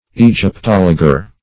Search Result for " egyptologer" : The Collaborative International Dictionary of English v.0.48: Egyptologer \E`gyp*tol"o*ger\, Egyptologist \E`gyp*tol"o*gist\, n. One skilled in the antiquities of Egypt; a student of Egyptology.